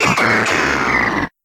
Catégorie:Cri Pokémon (Soleil et Lune) Catégorie:Cri de Quatermac